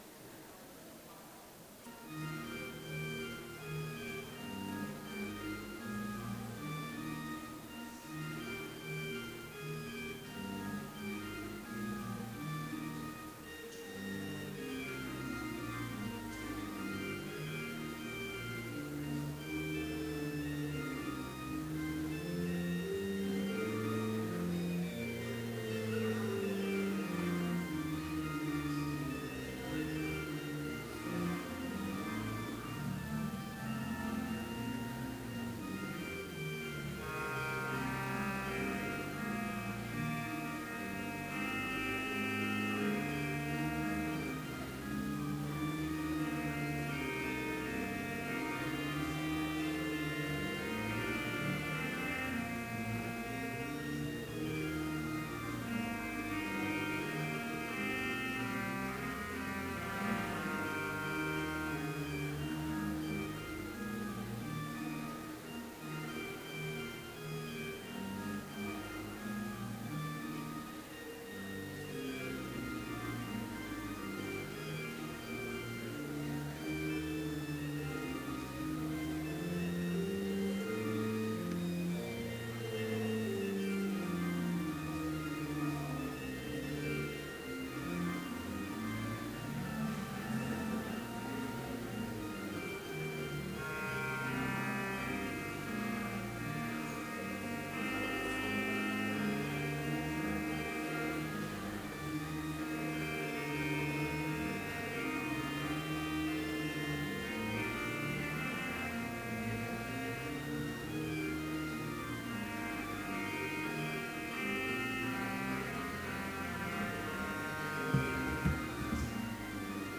Complete service audio for Chapel - November 16, 2015